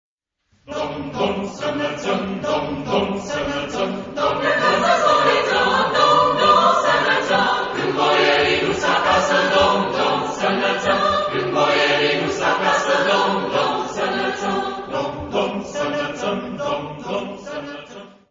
Noël roumain
Genre-Style-Forme : noël
Type de choeur : SATB  (4 voix mixtes )
Tonalité : sol majeur